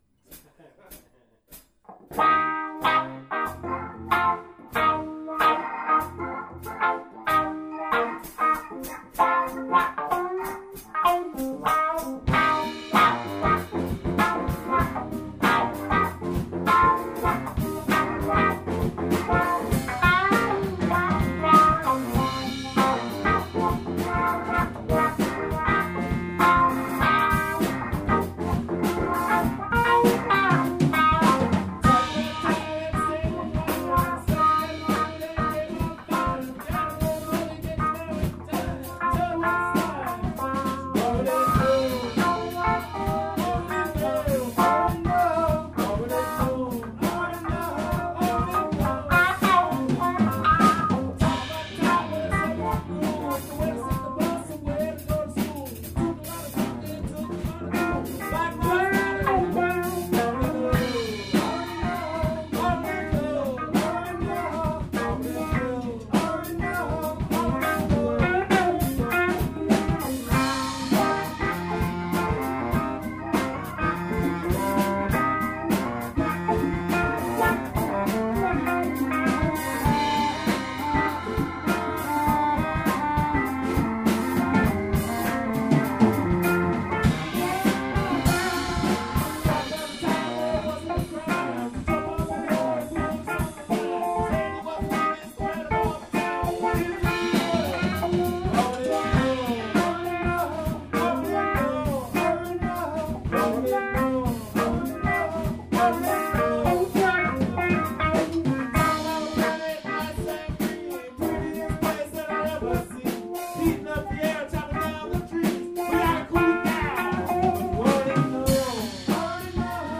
Rehearsal